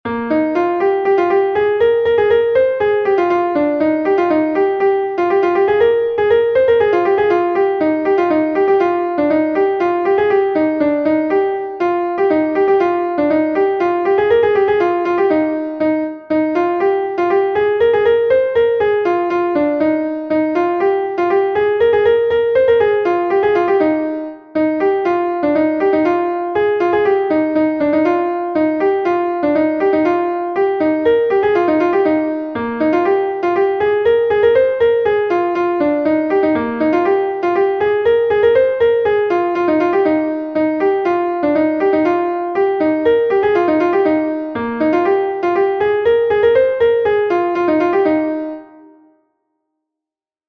Gavotenn Er Hroesti II is a Gavotte from Brittany